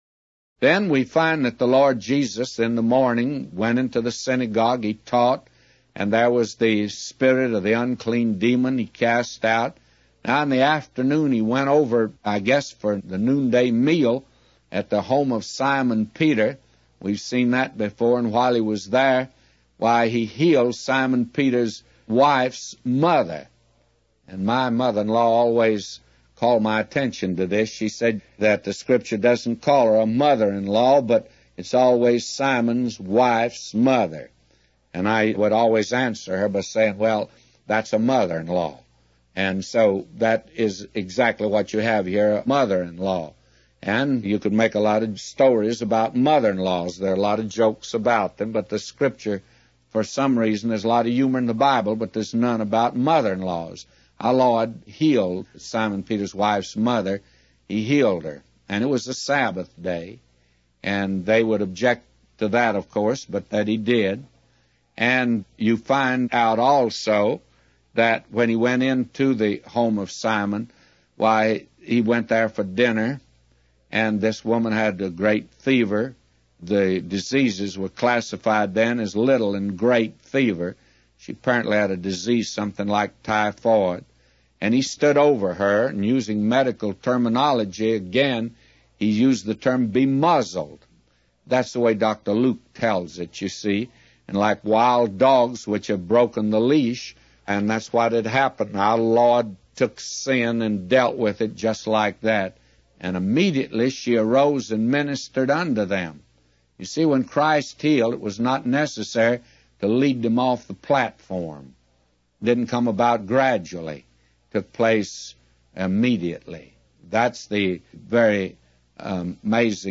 Evening Bible Reading - Luke 4